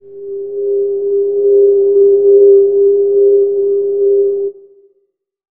Index of /90_sSampleCDs/Best Service ProSamples vol.36 - Chillout [AIFF, EXS24, HALion, WAV] 1CD/PS-36 WAV Chillout/WAV Synth Atmos 4